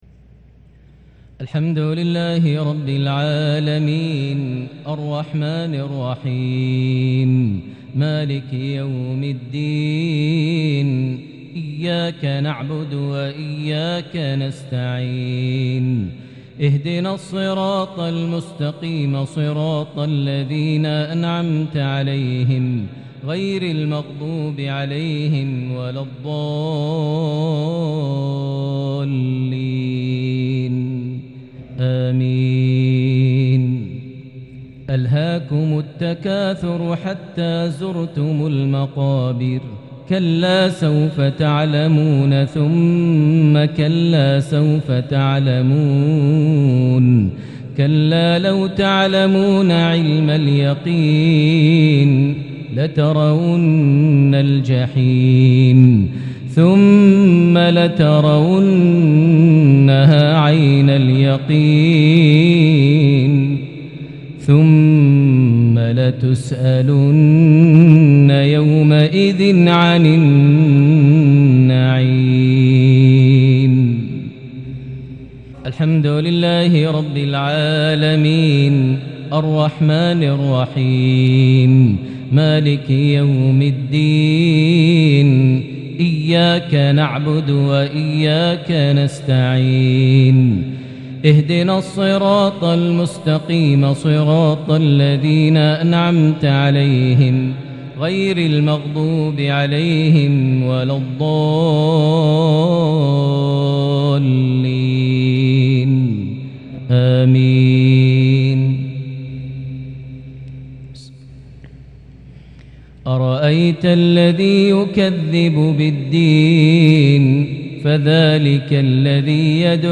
صلاة المغرب سورة التكاثر - الماعون | 2 ذو القعدة 1443هـ| maghrib 1-6-2022 prayer from Surah At-Takathur + Surah Al-Maun > 1443 🕋 > الفروض - تلاوات الحرمين